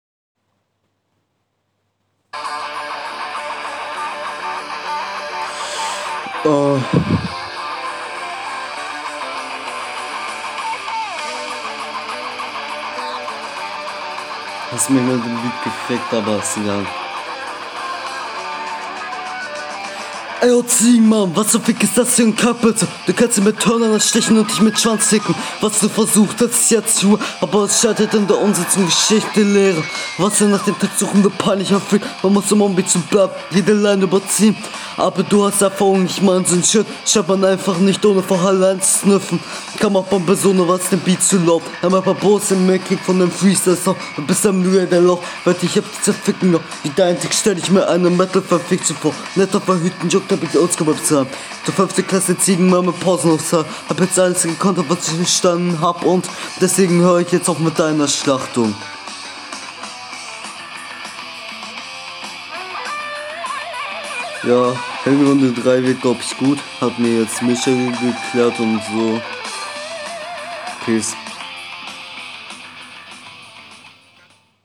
Ja gleiches Thema wie zuvor, vernuschelt, ev On Beat, ich verstehe hier keine Bar.
Ich versteh nicht was mit deiner Stimme und Aussprache passiert wenn du rappst.
Es tut mir echt Leid, aber man versteht einfach kein Wort.